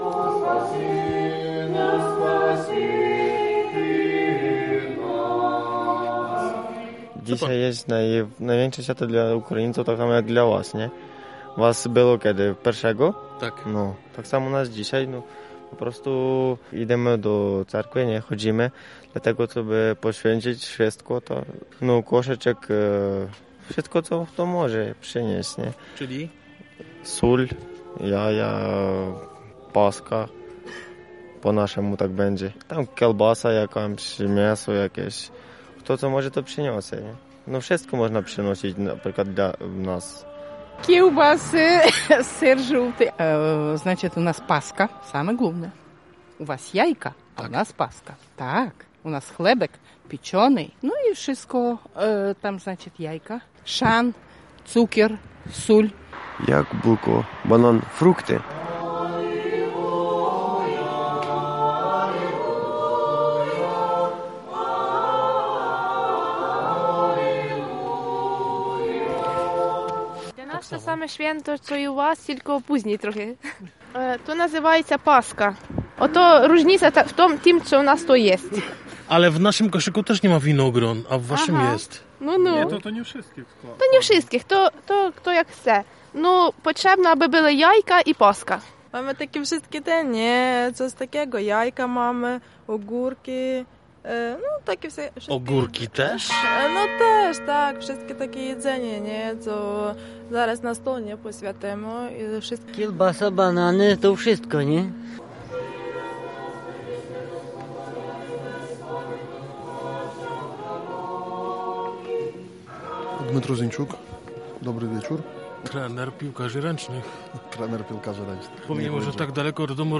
bardzo łanie śpiewają,
uwielbiam ten cerkiewny klimat !
wielkanoc-w-cerkwi-2018.mp3